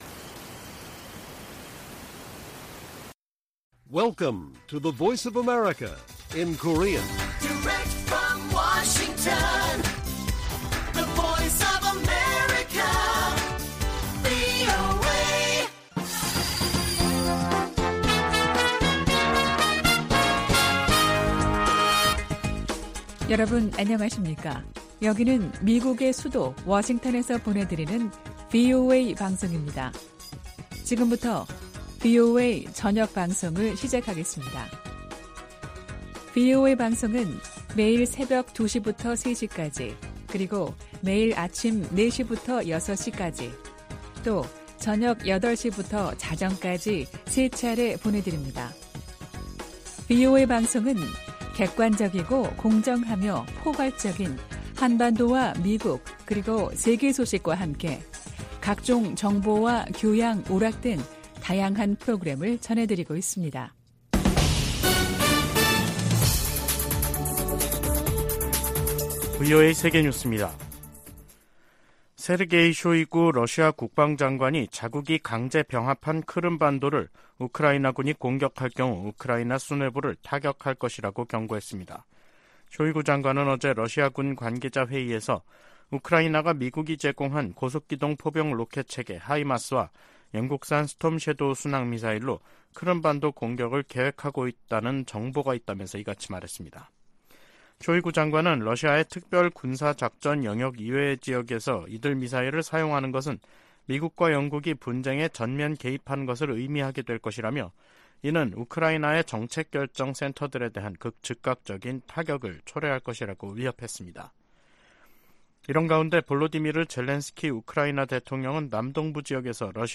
VOA 한국어 간판 뉴스 프로그램 '뉴스 투데이', 2023년 6월 21일 1부 방송입니다. 중국은 김정은 북한 국무위원장을 역내에서 가장 불안정한 존재로 인식하고 있다고 블링컨 미 국무장관이 말했습니다. 한국을 방문한 대니얼 크리튼브링크 미 국무부 차관보는 중국이 북한을 협상 테이블로 나오게 할 능력과 책임이 있다고 강조했습니다. 윤석열 한국 대통령과 에마뉘엘 마크롱 프랑스 대통령이 파리에서 회담하고 북한의 위협에 맞서 협력하기로 했습니다.